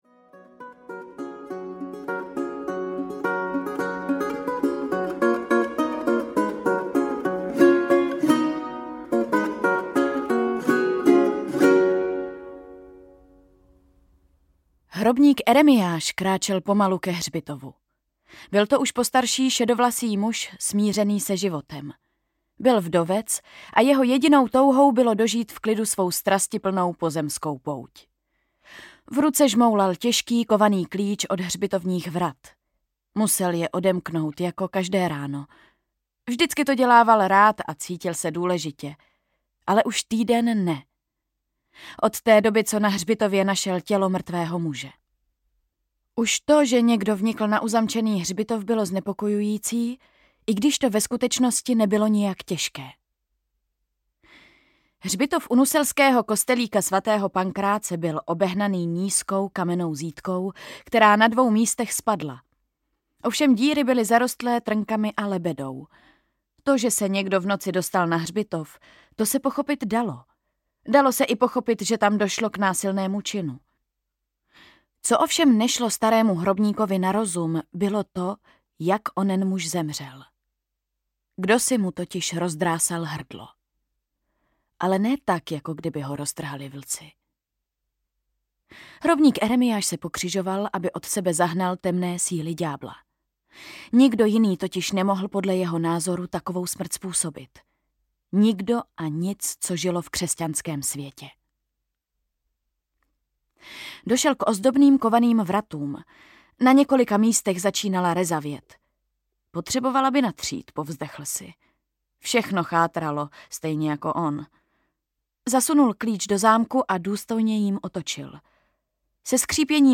Hřbitov upírů audiokniha
Ukázka z knihy
• InterpretEva Josefíková